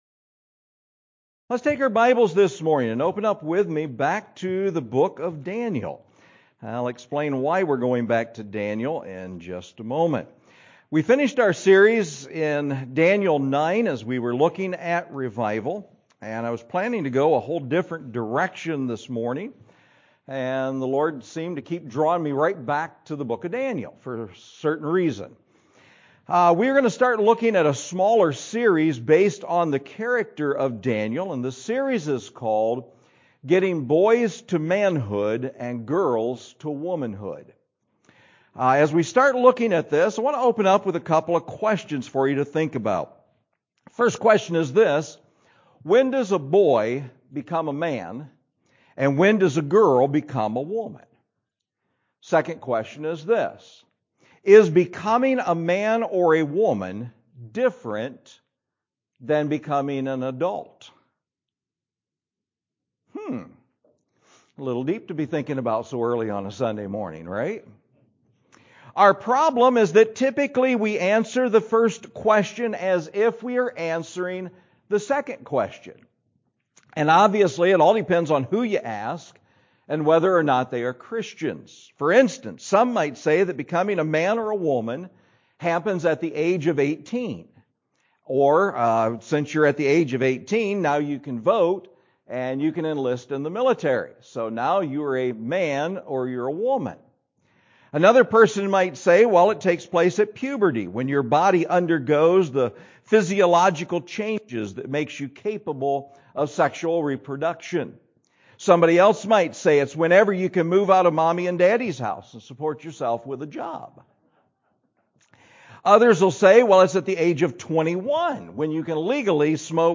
Getting Boys To Godly Manhood, Girls To Godly Womanhood – AM – 9/24/23 – First Baptist Church Bryan